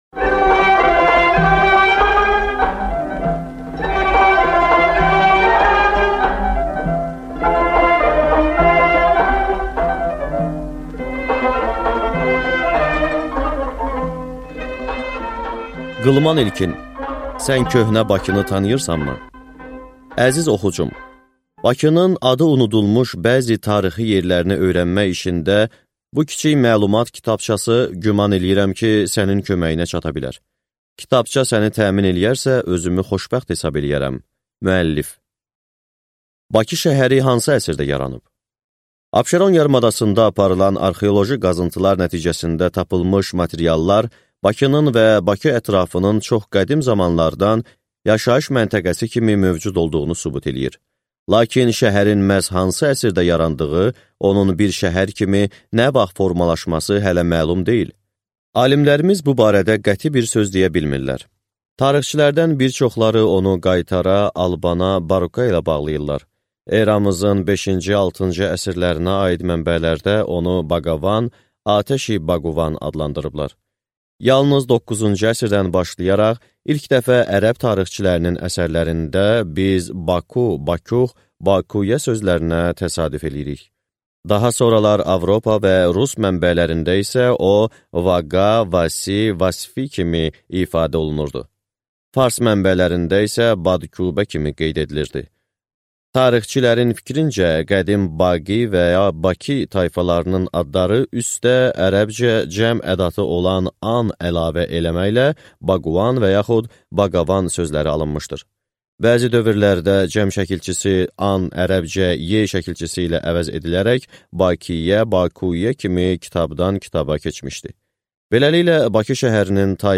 Аудиокнига Sən köhnə Bakını tanıyırsanmı | Библиотека аудиокниг